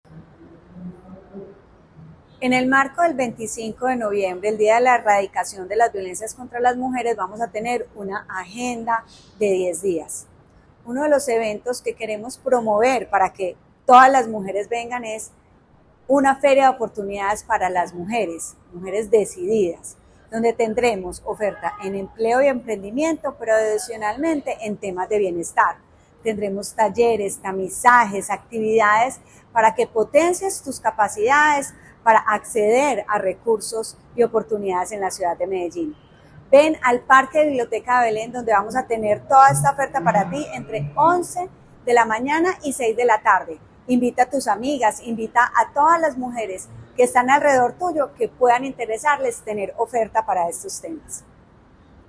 Palabras de Valeria Molina, secretaria de las Mujeres